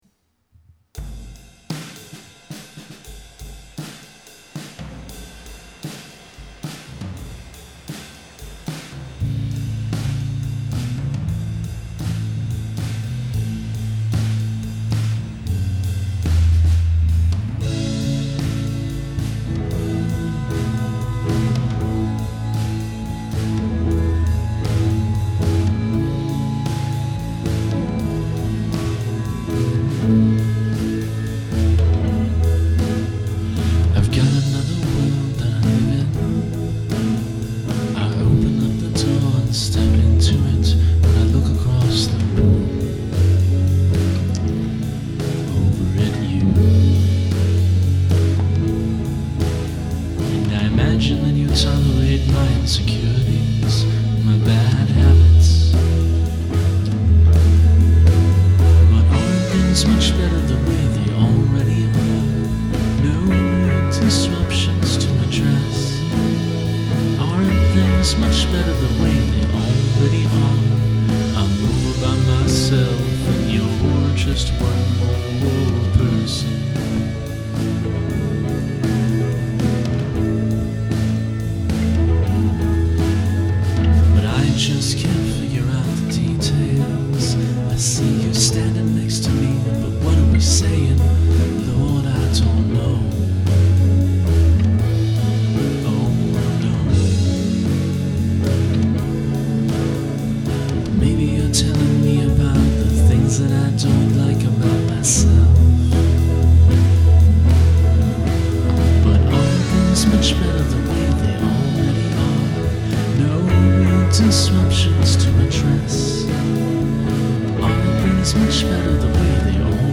one of those old plastic organs, drums, bass, and some classical guitar
Really pretty nice,but hard to hear the vocals.
Beautiful chorus, wonderful dreamy feel.